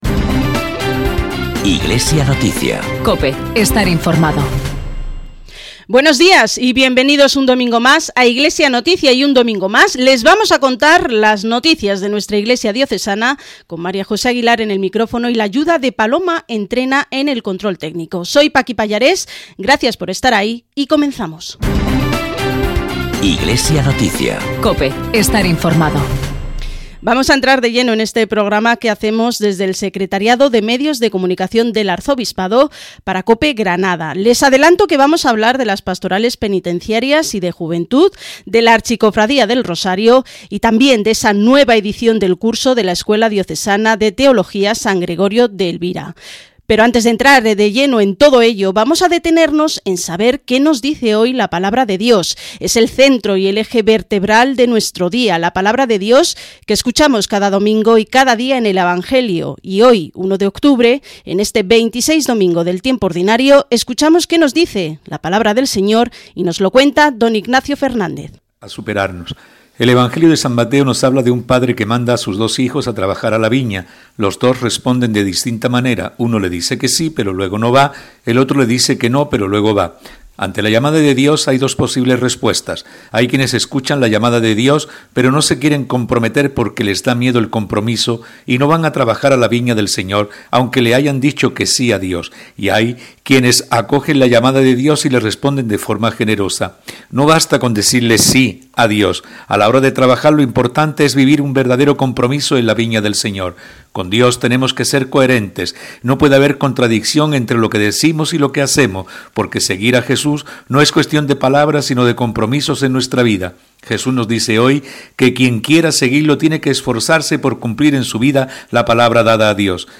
Programa informativo de la Archidiócesis de Granada, emitido el domingo 1 de octubre en COPE Granada, realizado por el Secretariado de Medios de Comunicación Social del Arzobispado.